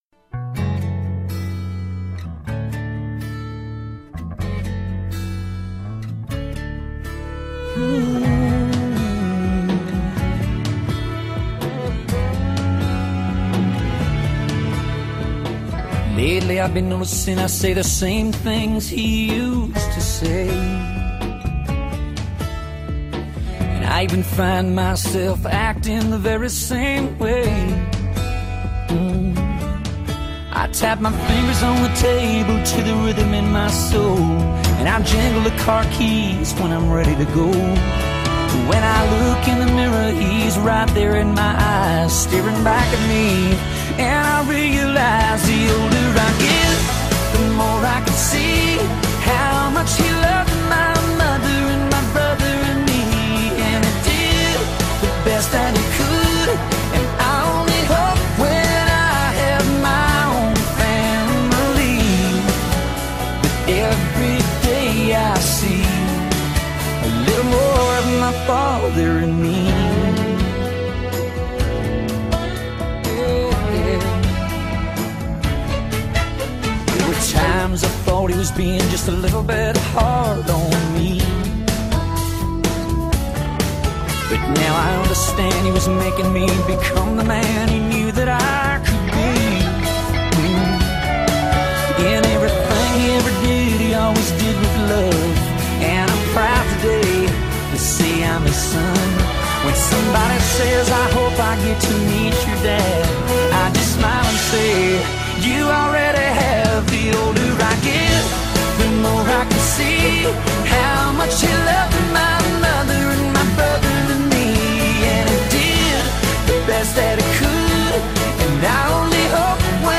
country rock